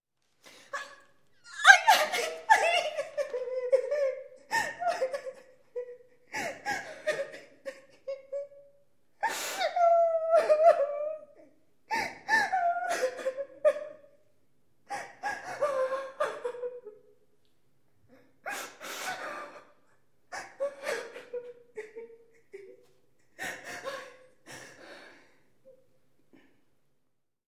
Плач женщины в пустом помещении